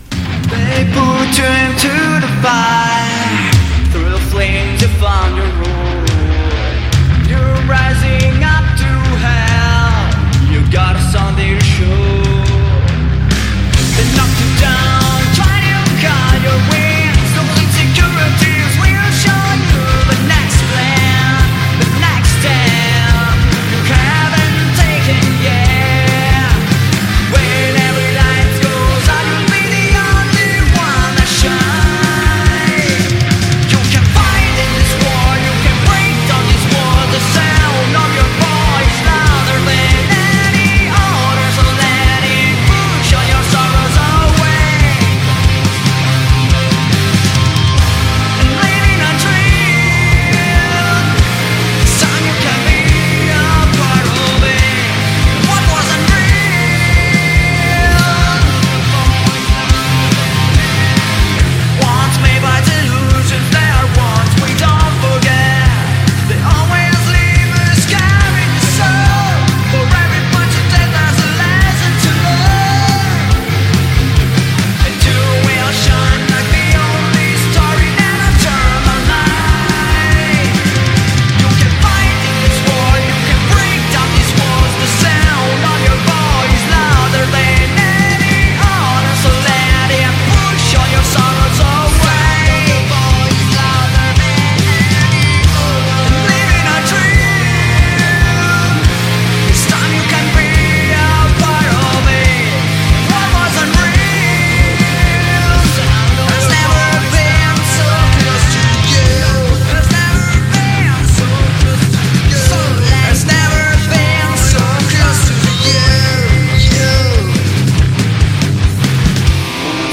Intervista agli Apòstasi |Rocktrotter | 7-2-22 | Radio Città Aperta